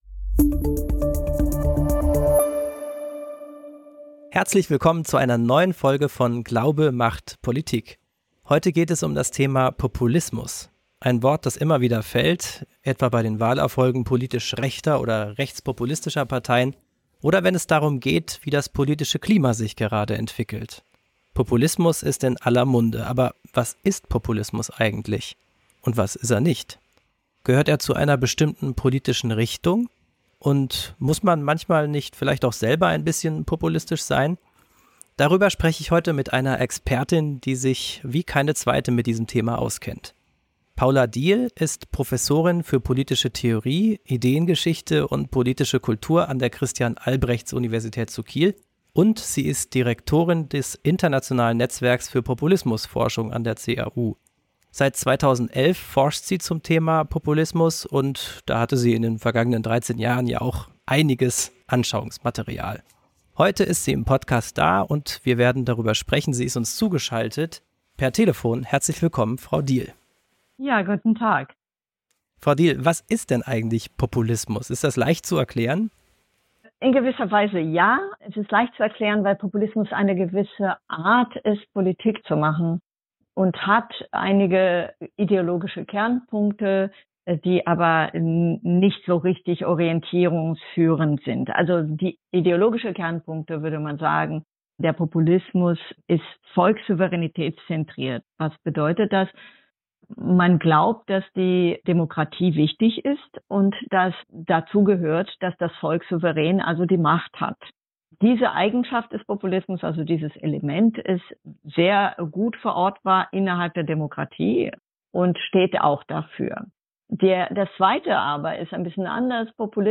(30) Was macht mich zum Populisten? Ein Gespräch